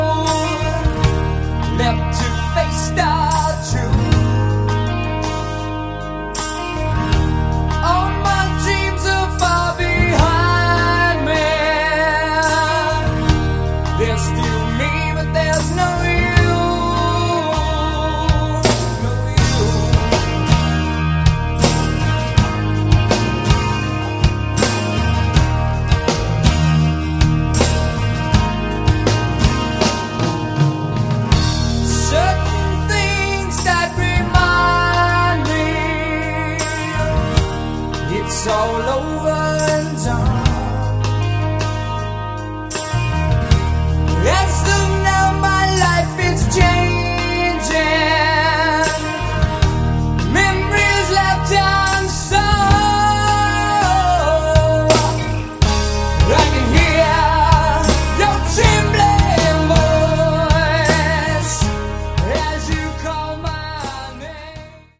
Category: Hard Rock
acoustic Ballad